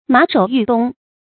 馬首欲東 注音： ㄇㄚˇ ㄕㄡˇ ㄧㄩˋ ㄉㄨㄙ 讀音讀法： 意思解釋： 謂東歸；返回。